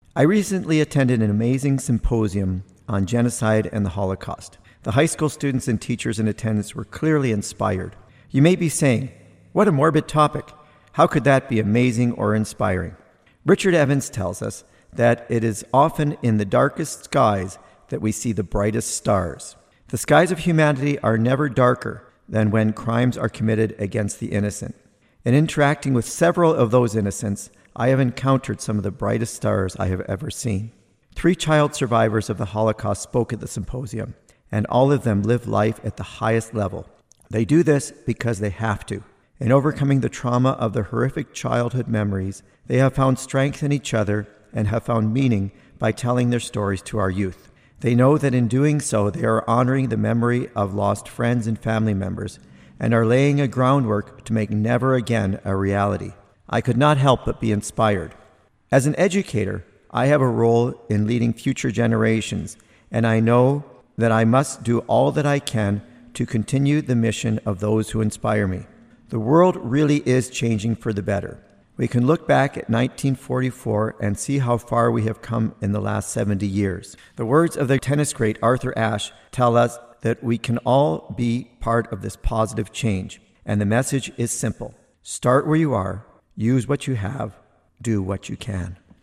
Recording Location: CFIS-FM, Prince George
Type: Commentary
224kbps Stereo